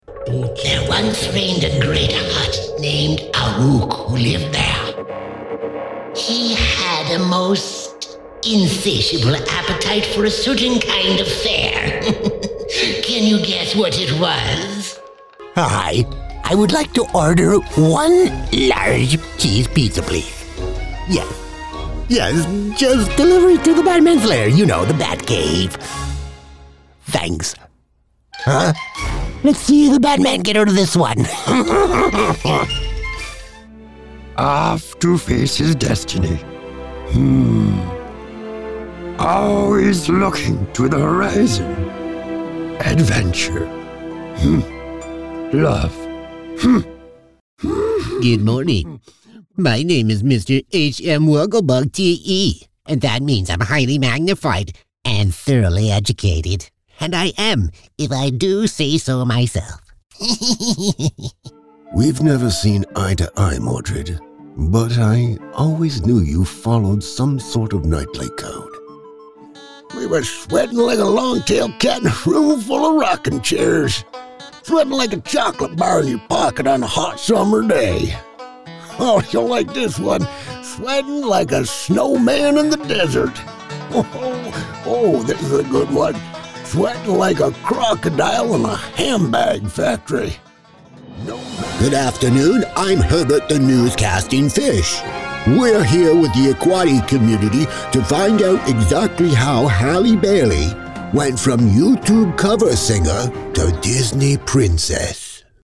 Character Demo
canadian gen-american
Middle Aged